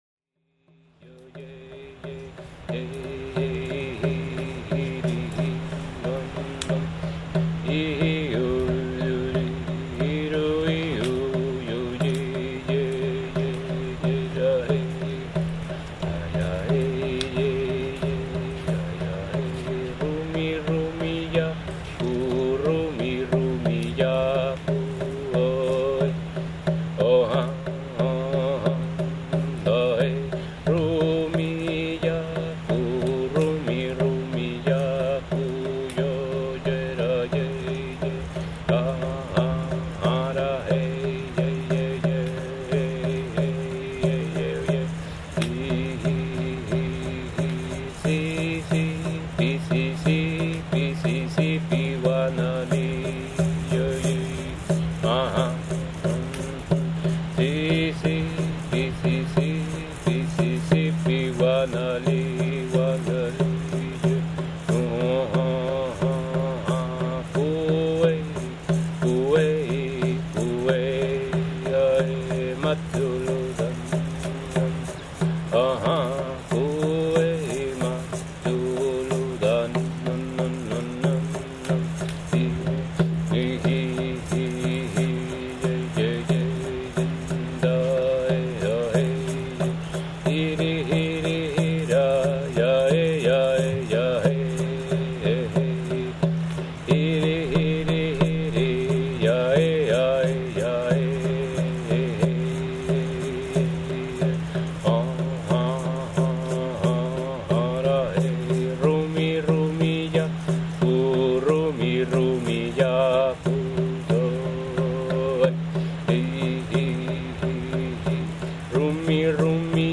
music-shaman-prayers.mp3